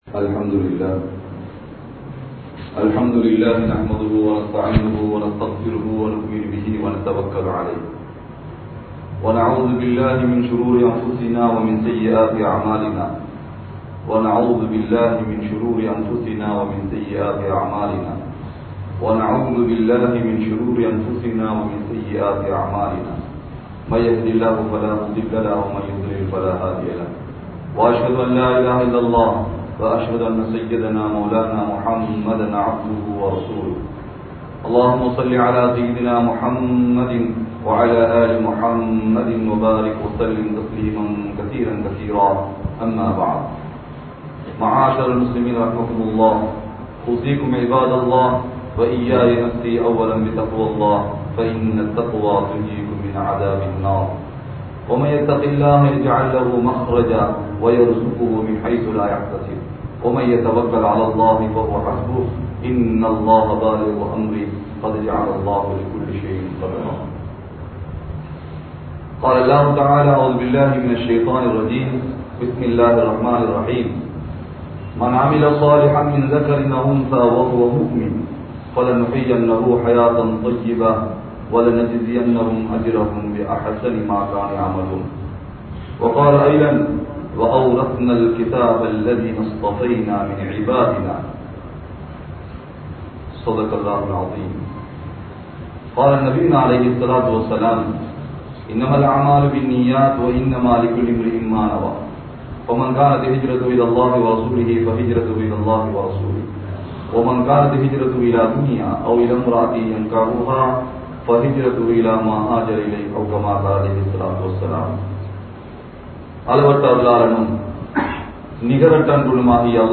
Intha Ummaththin Perumai Al Quran (இந்த உம்மத்தின் பெருமை அல்குர்ஆன்) | Audio Bayans | All Ceylon Muslim Youth Community | Addalaichenai
Pussallawa Jumua Masjidh